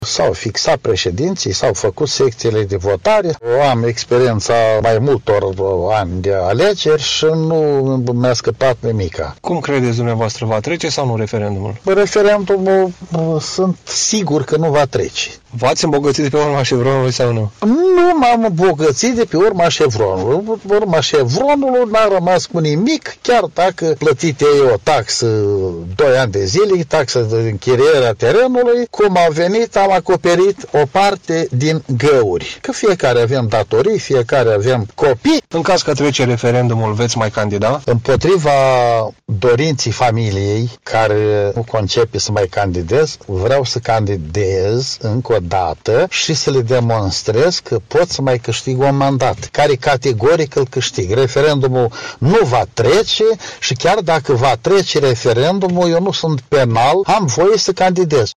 Mircia Vlasă susține, într-un interviu acordat reporterului nostru